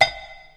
• 木块
wood_block.wav